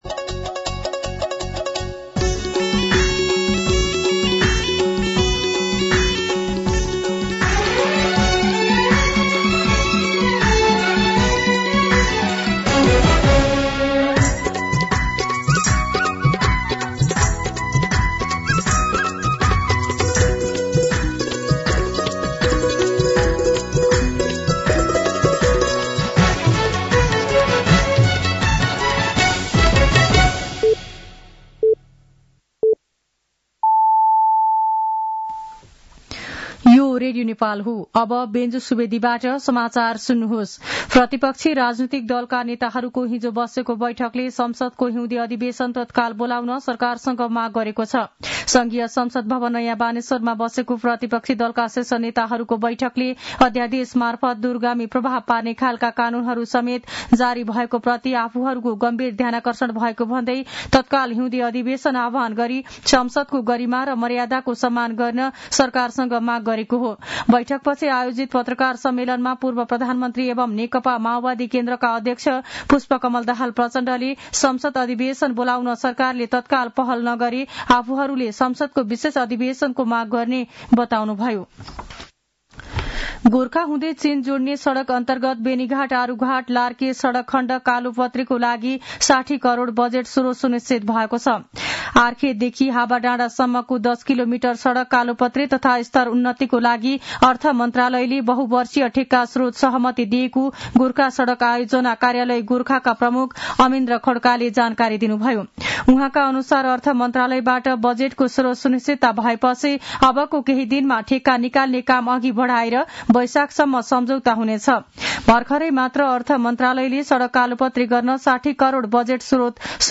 मध्यान्ह १२ बजेको नेपाली समाचार : ६ माघ , २०८१
12-pm-Nepali-News-1.mp3